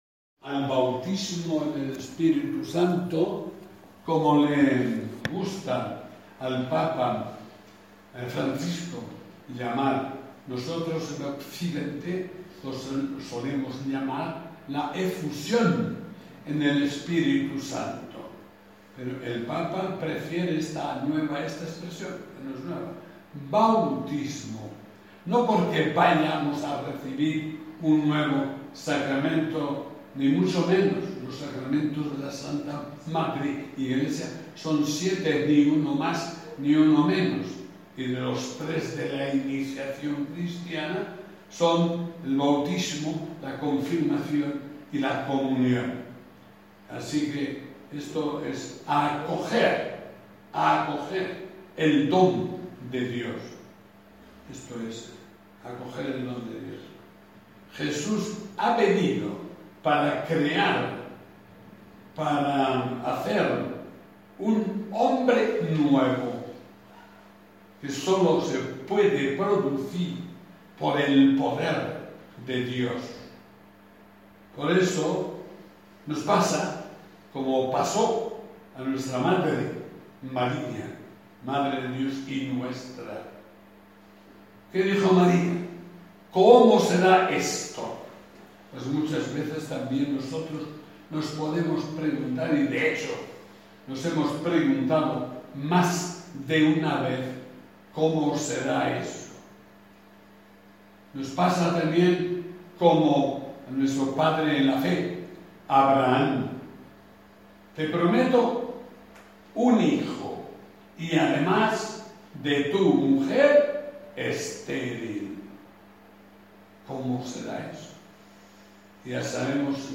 Homilia_Bautismo_en_el_Espiritu.mp3